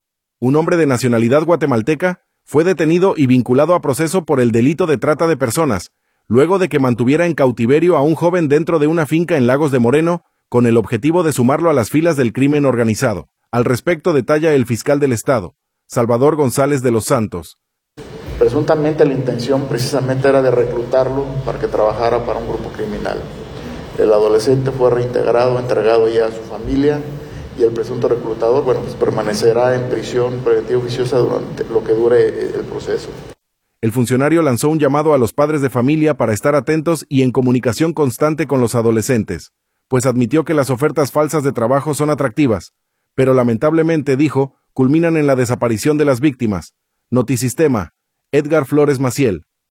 Un hombre de nacionalidad guatemalteca, fue detenido y vinculado a proceso por el delito de trata de personas, luego de que mantuviera en cautiverio a un joven dentro de una finca en Lagos de Moreno, con el objetivo de sumarlo a las filas del crimen organizado. Al respecto detalla el fiscal del estado, Salvador González de los Santos.